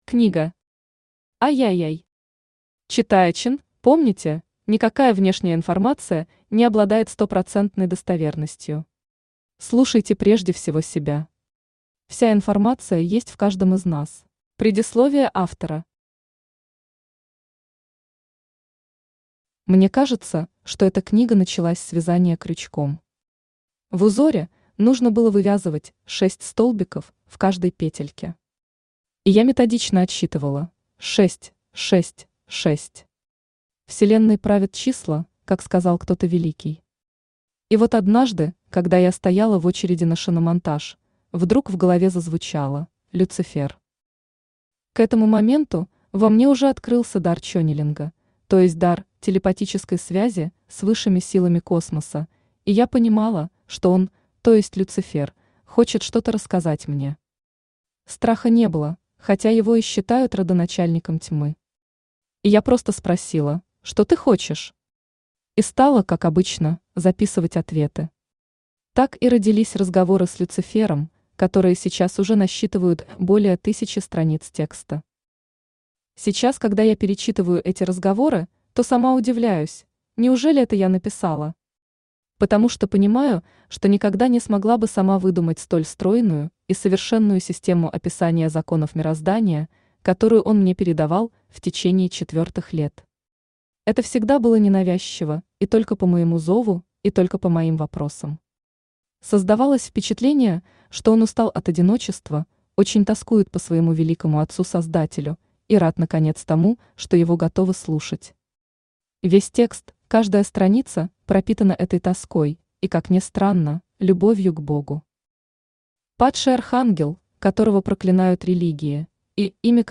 Аудиокнига Разговор с Люцифером. Книга III | Библиотека аудиокниг
Книга III Автор Елена Сидельникова Селена Читает аудиокнигу Авточтец ЛитРес.